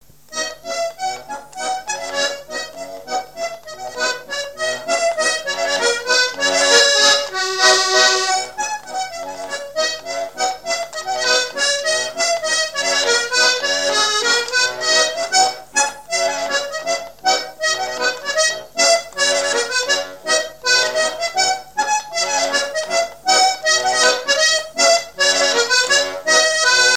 Mémoires et Patrimoines vivants - RaddO est une base de données d'archives iconographiques et sonores.
danse : scottish
Pièce musicale inédite